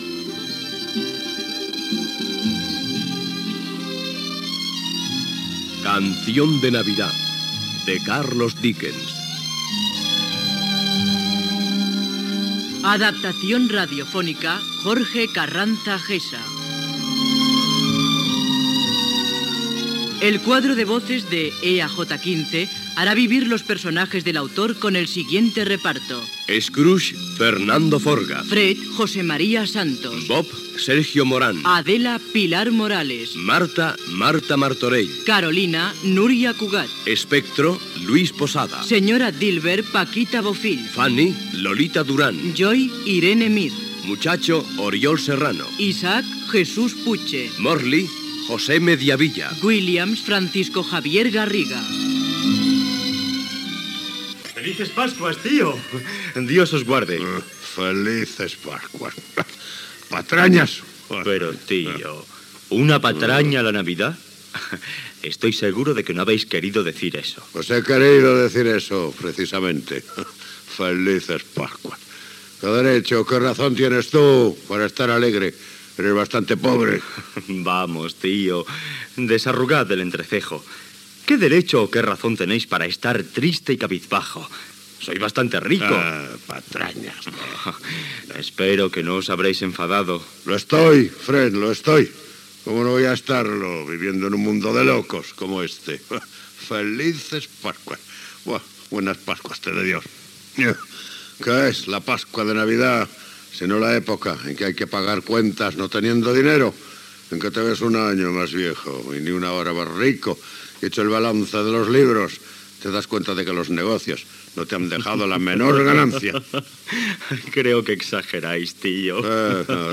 Careta de l'emissió amb el repartiment dels actors que participen a l'adaptació radiofònica de "Canción de Navidad" de Charles Dickens i una escena de l'obra.
Ficció